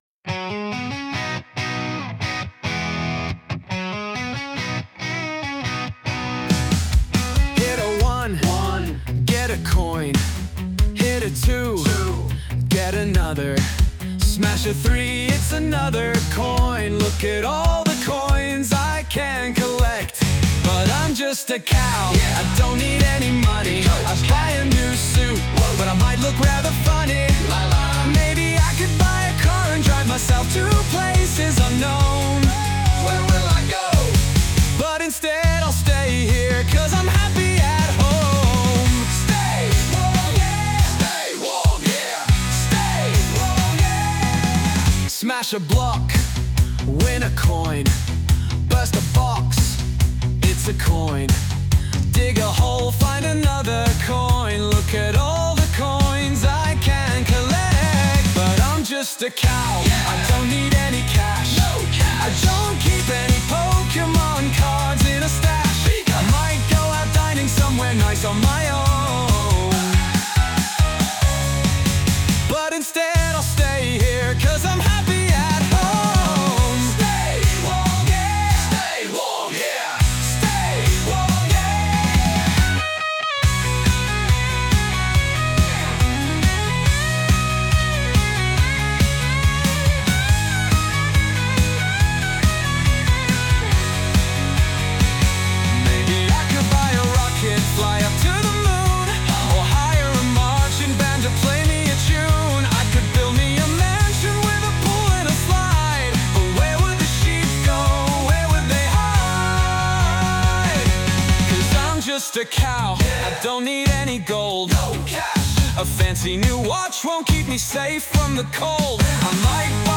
Alternative version (This wasn't seeded by the song, and I just left it to its own devices)
Sung by Suno